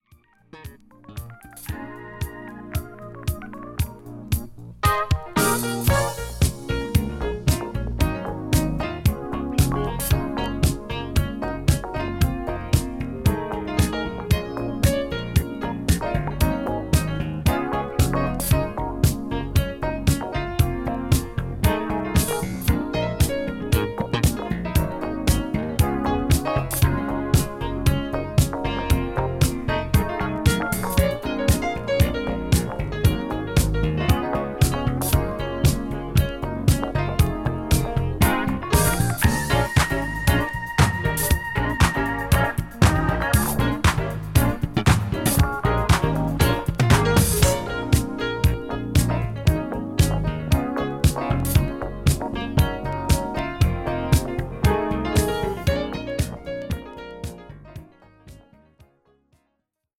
ホーム ｜ SOUL / FUNK / RARE GROOVE / DISCO > SOUL